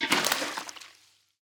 Minecraft Version Minecraft Version latest Latest Release | Latest Snapshot latest / assets / minecraft / sounds / item / bucket / empty_fish1.ogg Compare With Compare With Latest Release | Latest Snapshot
empty_fish1.ogg